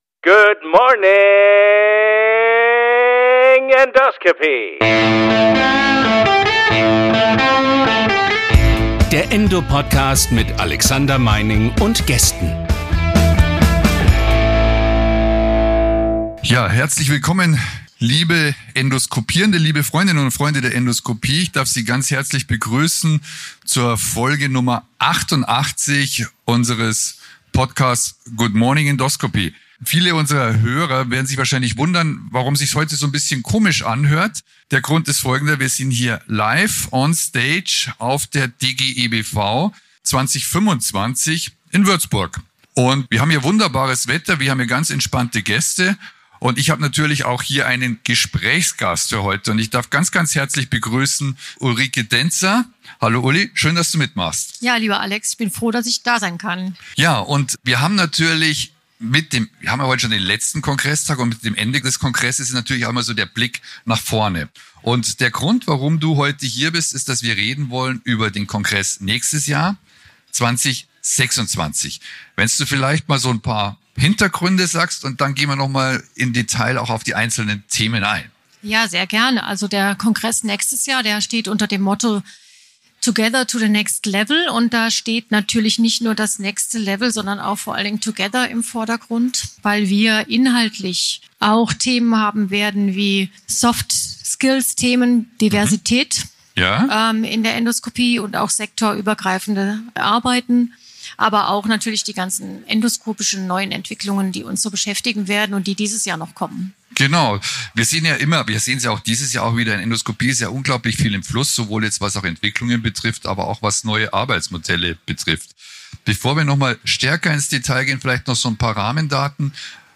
Live vom DGE-BV 2025 der Blick auf 2026